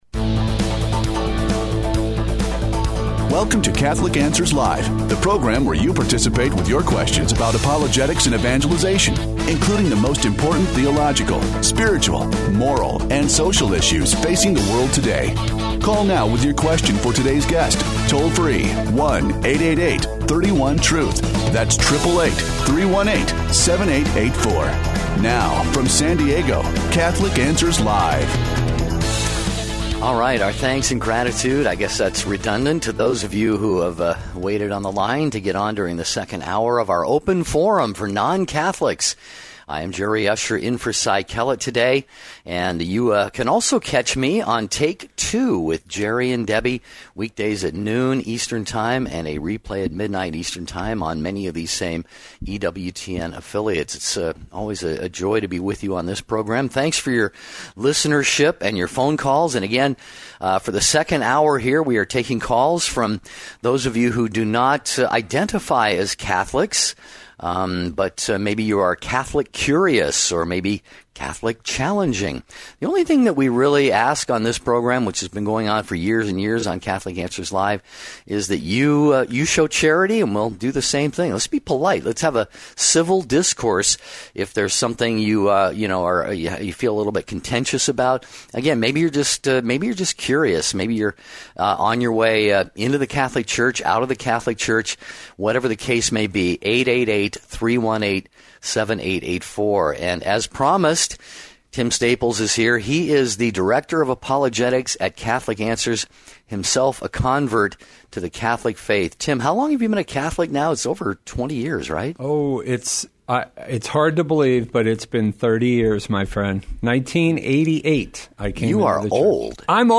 answers questions about the Faith from non-Catholics